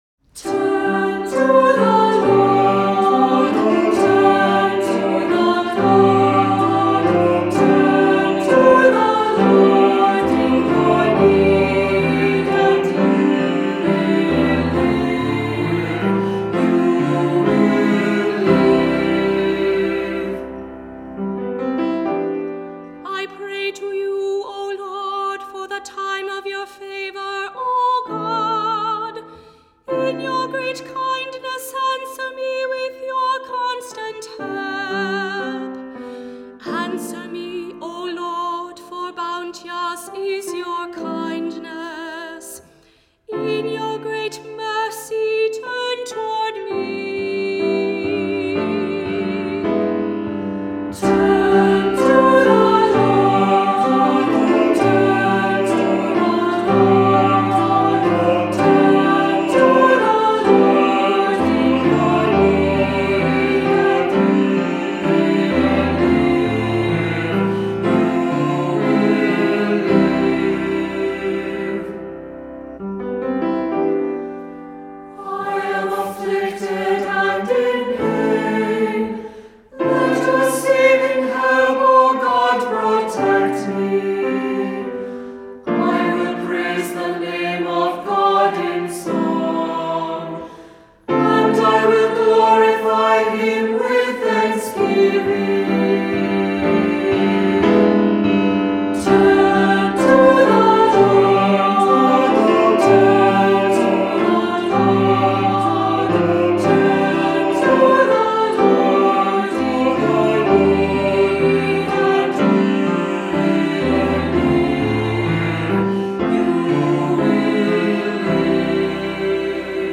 Voicing: Two-part mixed choir; Cantor; Assembly